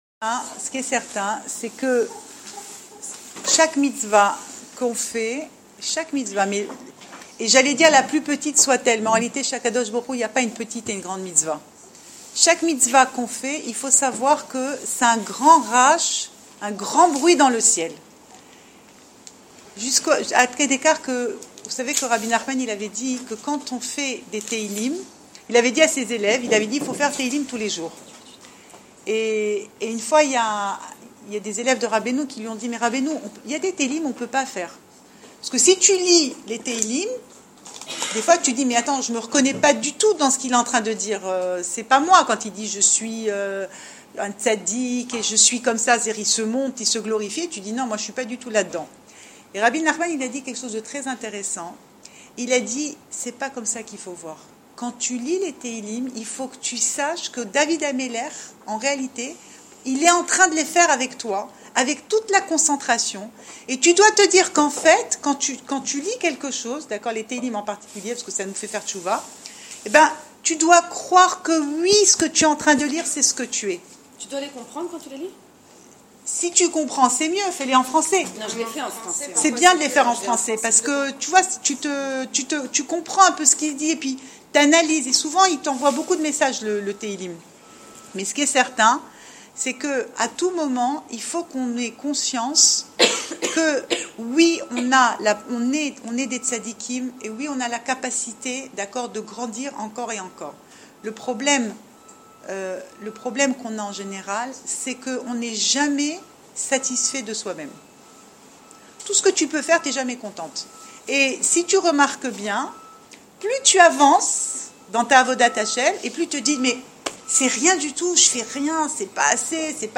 Cours audio Le coin des femmes Pensée Breslev - 23 février 2016 3 avril 2016 Chaque mitzva fait un bruit ENORME dans les chamayim ! Enregistré à Raanana